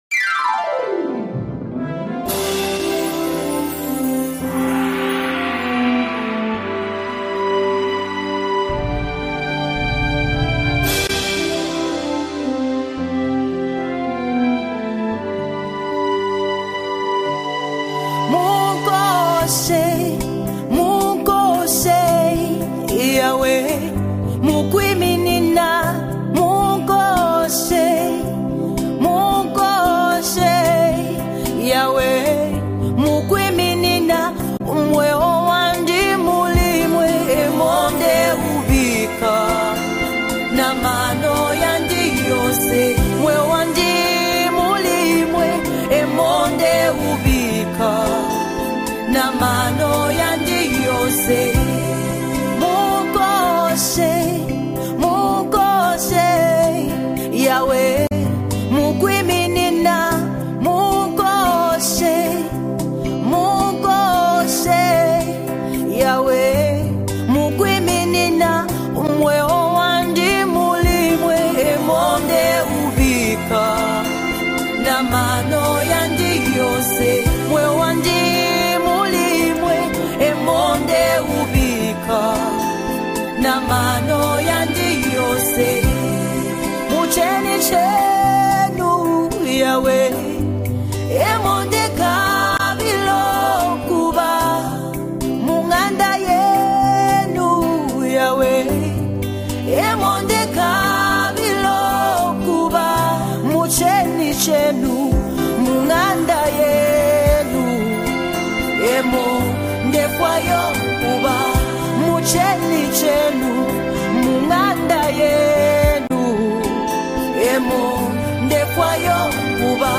Gospel Music
spiritually uplifting song
Known for her soulful and captivating voice
beautiful worship song